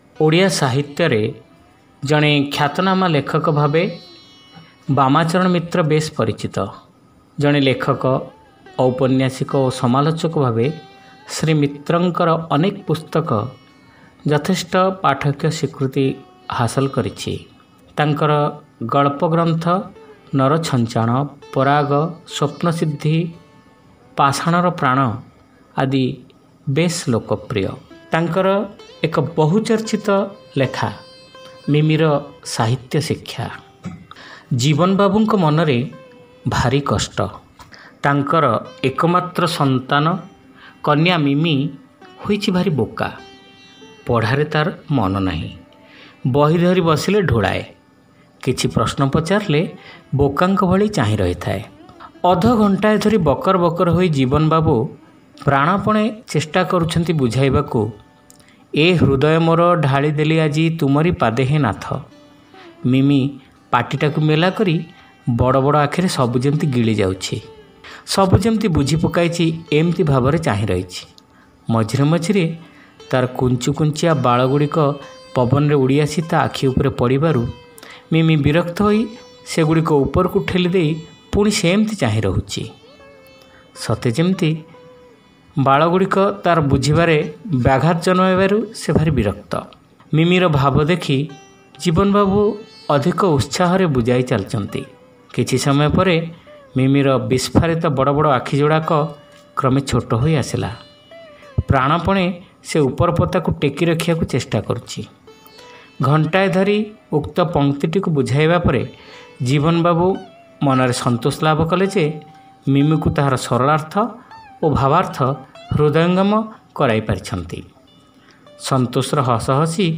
ଶ୍ରାବ୍ୟ ଗଳ୍ପ : ମିମିର ସାହିତ୍ୟ ଶିକ୍ଷା